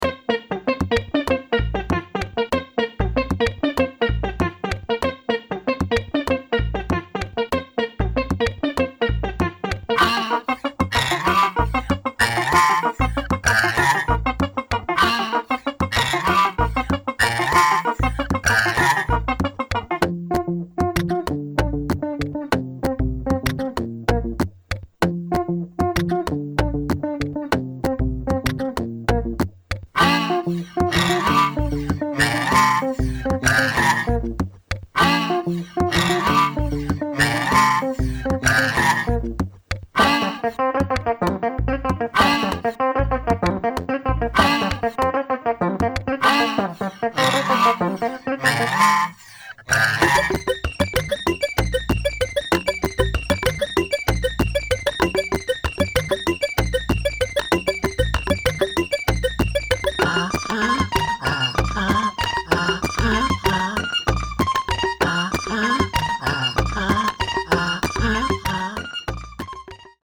明石とベルリンの遠隔セッションで制作されたという本作